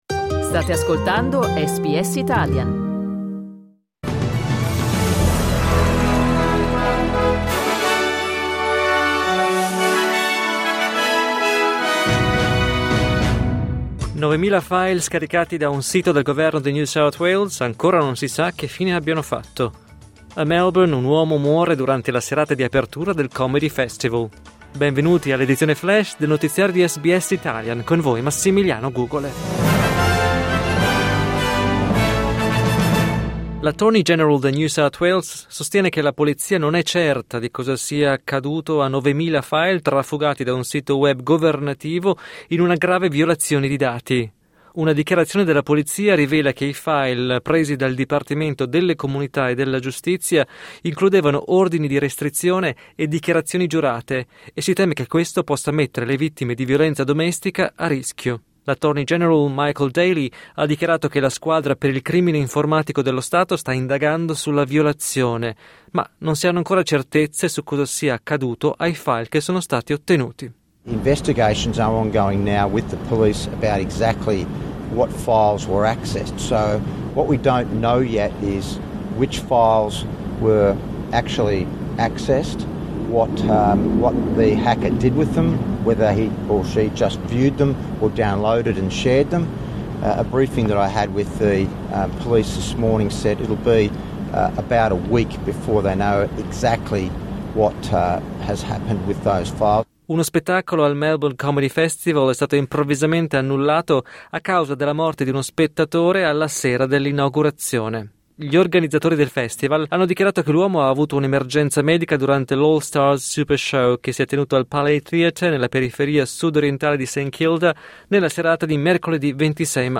News flash giovedì 27 marzo 2025